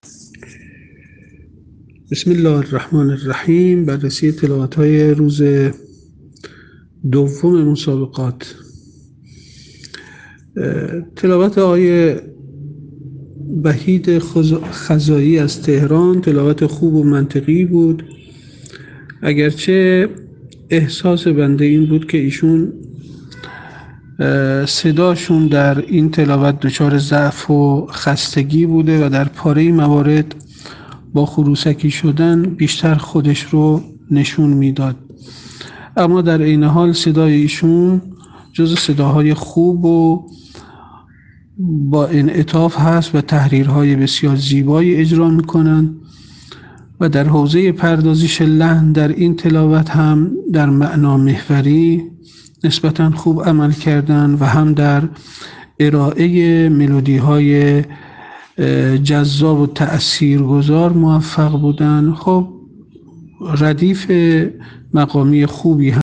فیلم اجرای قاریان فینالیست در دومین شب مسابقات سراسری قرآن
صدای ایشان در این تلاوت دچار ضعف و خستگی بود و در پاره‌ای موارد با خروسکی شدن بیشتر خود را نشان داد، اما در عین حال جزء صداهای خوب و با انعطاف است و تحریرهای بسیار زیبایی اجرا می‌کند.
ایشان در حوزه پردازش لحن در معنامحوری نسبتاً خوب عمل کرد و در بحث ارائه ملودی‌های جذاب و تأثیرگذار هم موفق بود. همچنین تلاوت ایشان از ردیف‌های مقامی خوبی برخوردار بود.